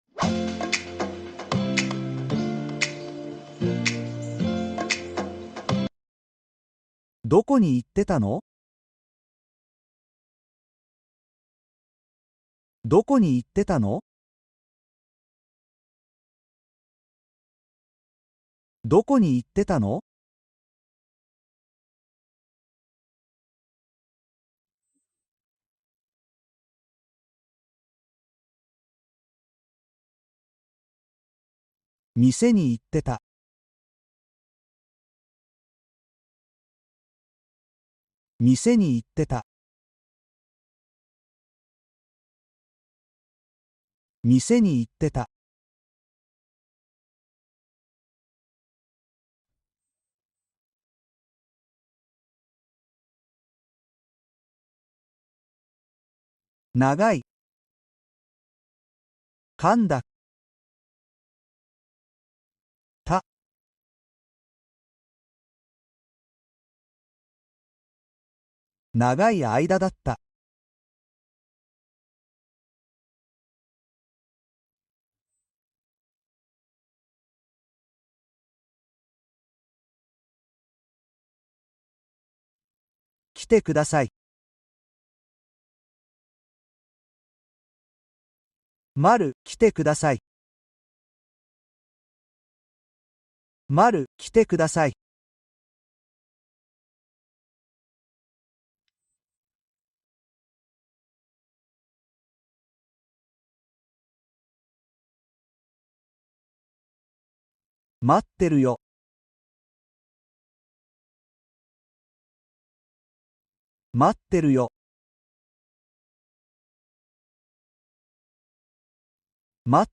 Frases fáciles y lentas para conversaciones básicas